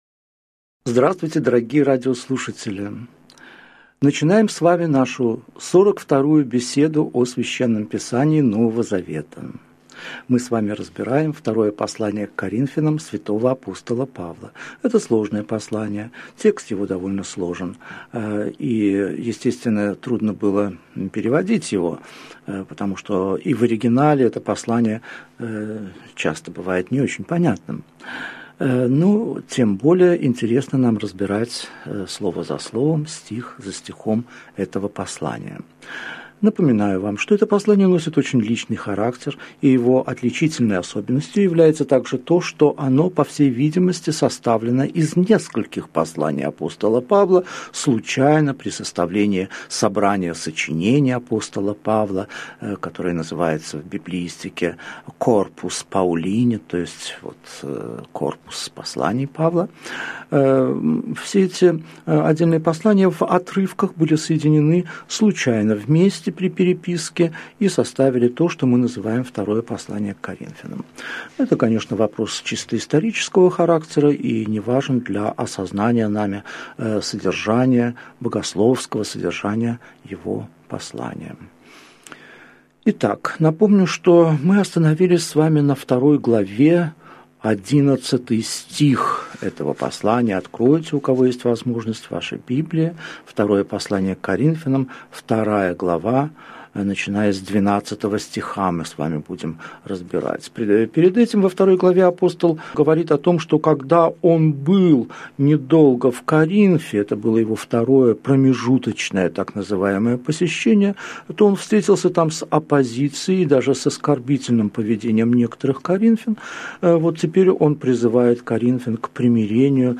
Аудиокнига Беседа 42. Второе послание к Коринфянам. Глава 2 стихи 12 – 17 | Библиотека аудиокниг